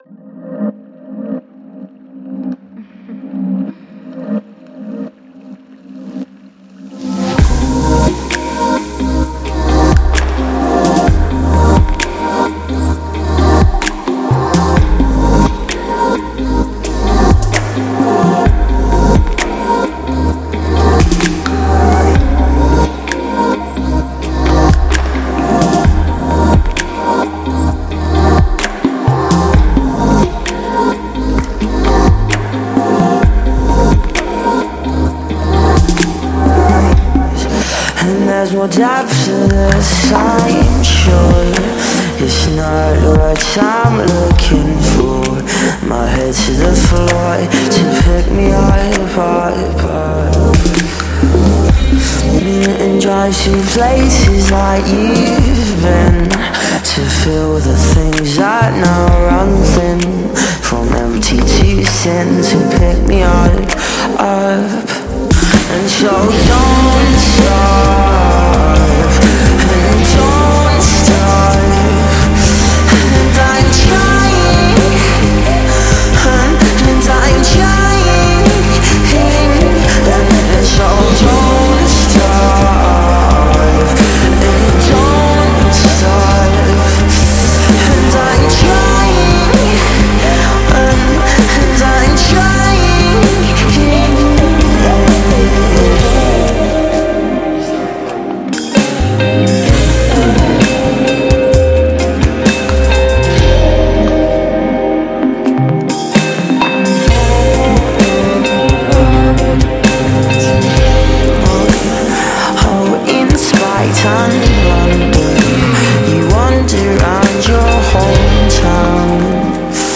Just vibey consistency, I love it.